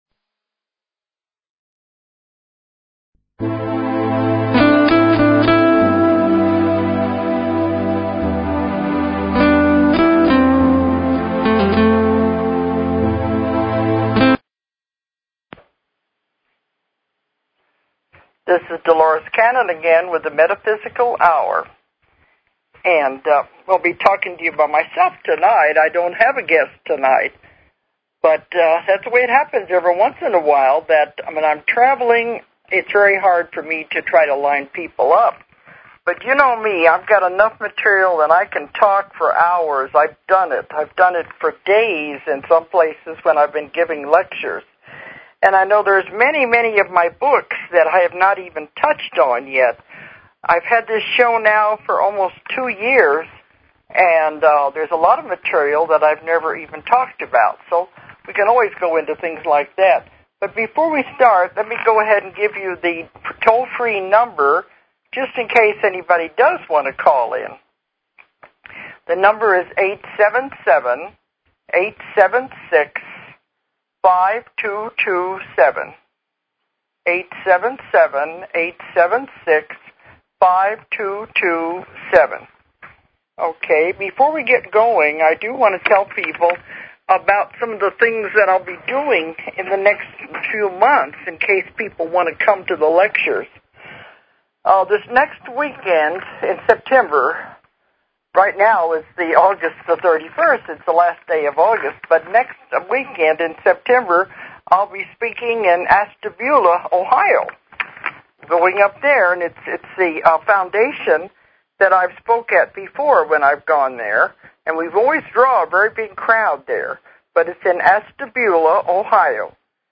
A Helper called with a Medical Problem